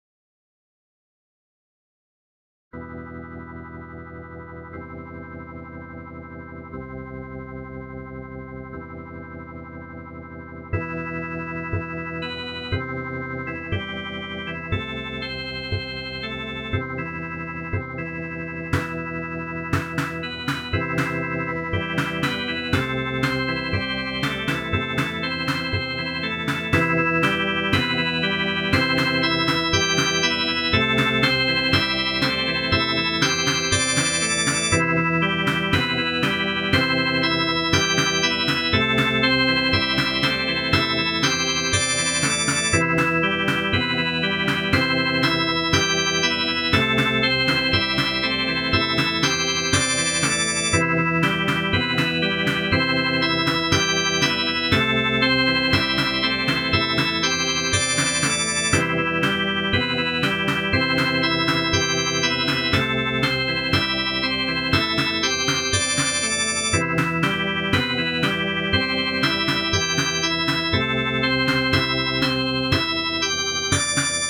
If you don't feel like waiting and downloading SuperCollider, or you don't own a Mac (there are versions of SuperCollider for Linux and Windows, though they're not as complete), here's what it sounds like when run in the program (recorded straight from the program with no compression or editing, so there's some silence at the beginning and it starts out pretty quiet):
You might have also noticed from the text or the recording that there's a kind of Leslie effect going on, which I did try as part of an attempt to make the droning sine waves sound a little bit more like a real Hammond organ (just a little panning and tremolo, no attempt to recreate the Doppler effect or tonewheel leaking). I also recreated a bass and snare drum sound (which I think both sounded better than my organ attempt) to make it a little more interesting, which wasn't entirely inappropriate as many of these machines had simple analog rhythm machines built in.
hammond_demo.ogg